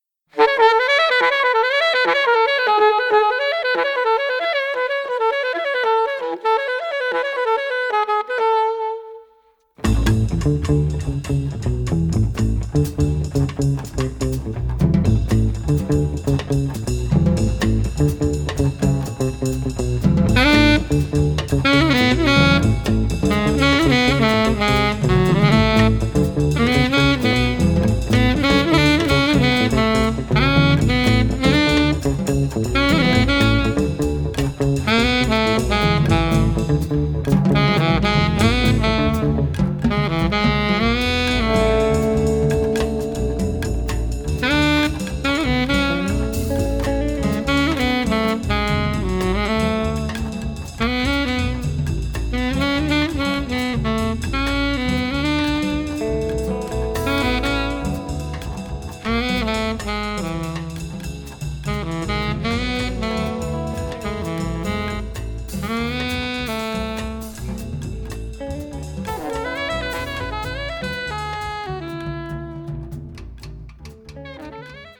Caribbean-influenced
evokes images of a lovely evening on the beach.